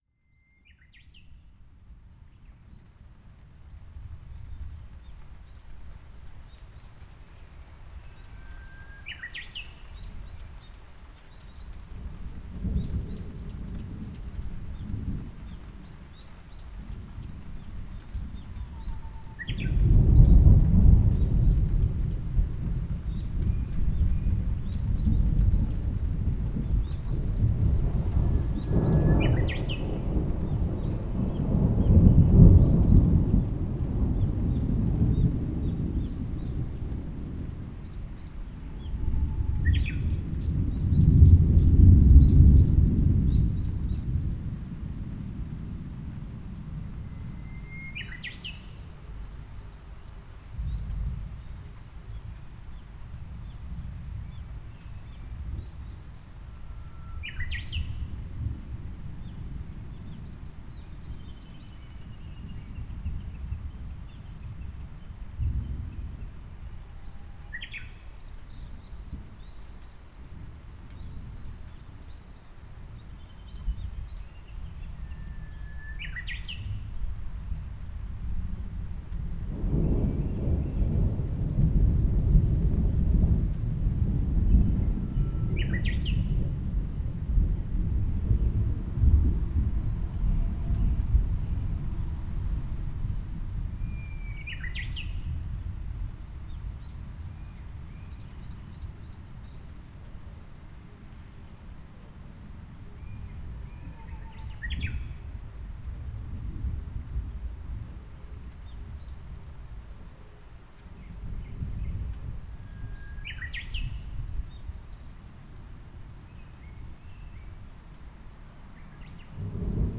ZOOM H6, Sennheiser MKH8020, 2020年5月12日 山形県酒田市
そのサクラの下にマイクをセットして録音しました。遠くでアオサギの声も響きます。
右手にずっとファンの音が聞こえていますが、あとで電照菊の温室からの音と分かりました。